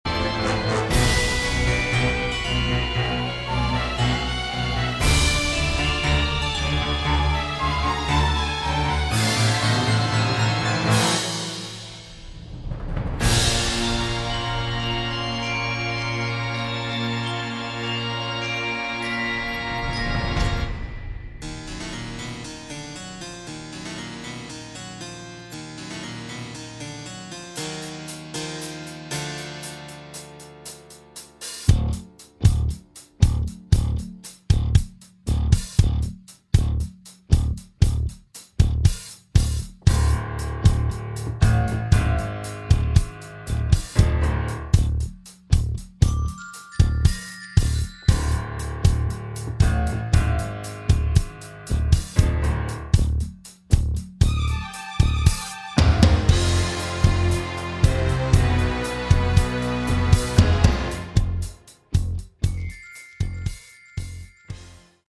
Category: Shock Rock/Horror Rock